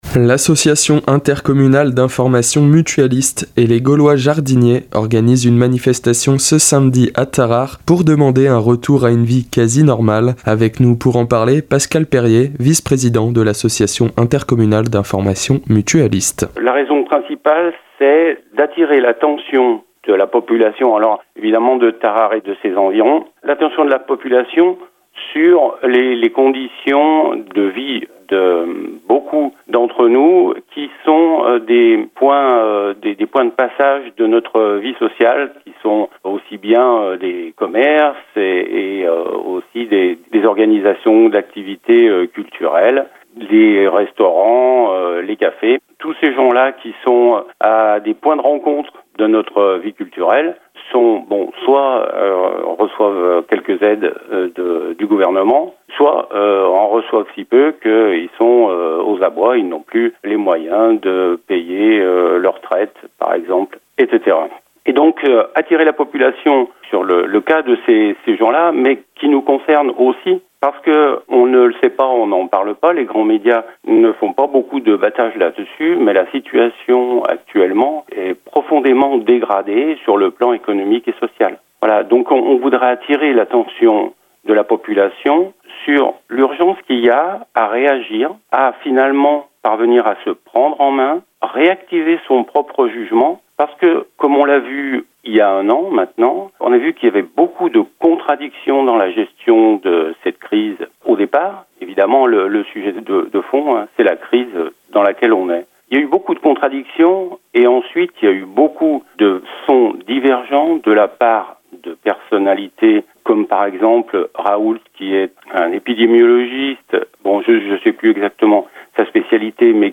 Interview Radio Val de Reims